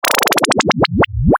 game_over.mp3